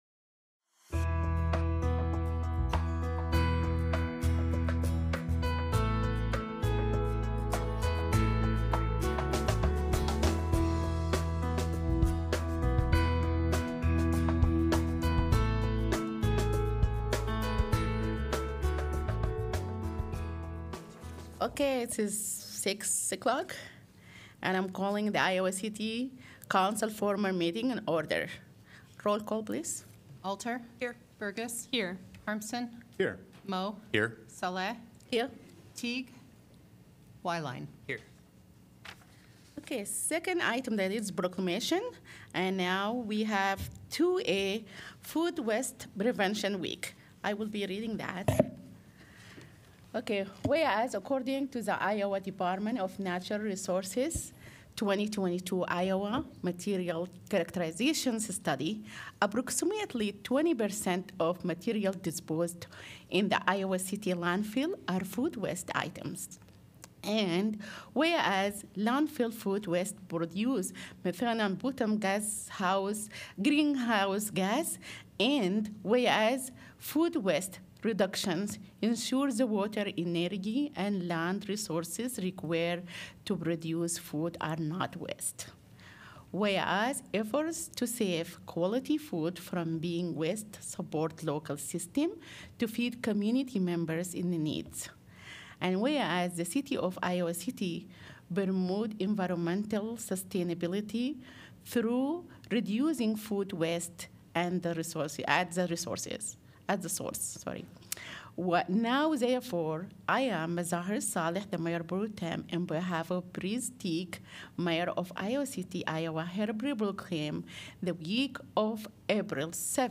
Iowa City City Council Meeting of April 1, 2025
Gavel-to-gavel coverage of the Iowa City City Council meeting, generally scheduled for the first and third Tuesday of each month.